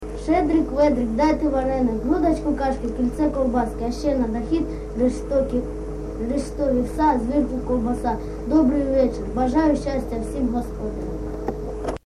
ЖанрЩедрівки
Місце записус. Келеберда, Кременчуцький район, Полтавська обл., Україна, Полтавщина